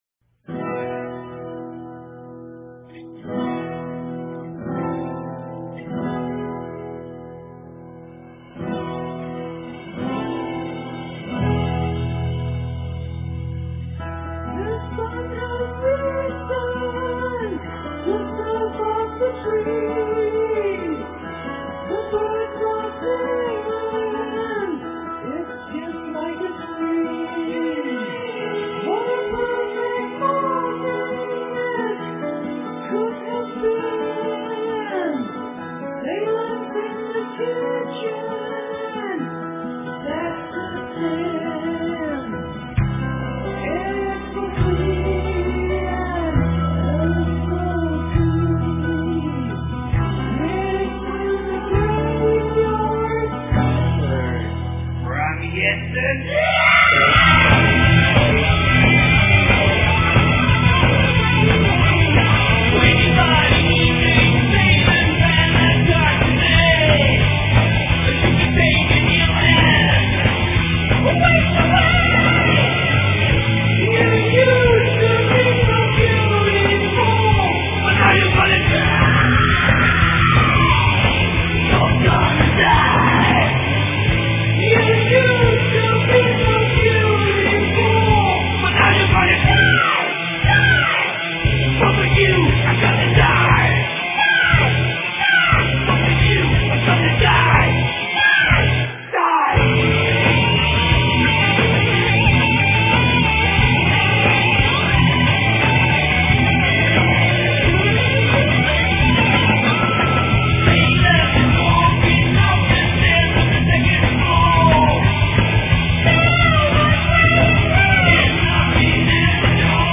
Black Metall